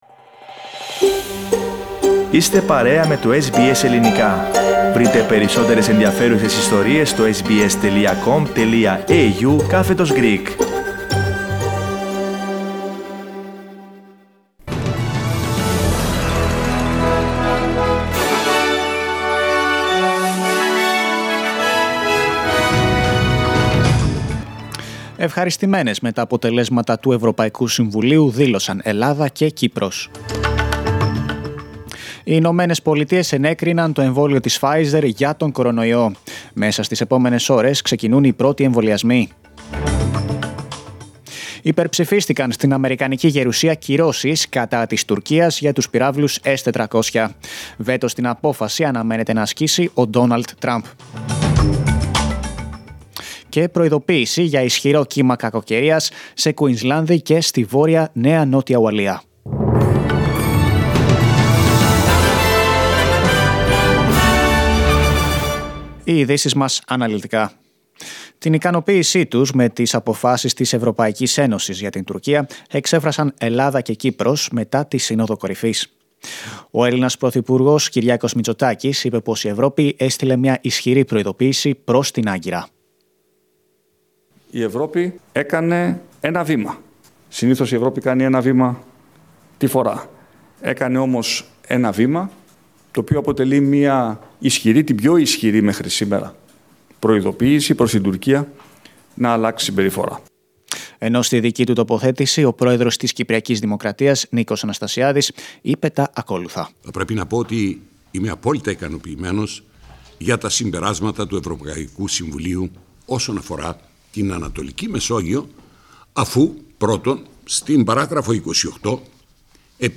Δελτίο Ειδήσεων Σάββατο 12 Δεκεμβρίου 2020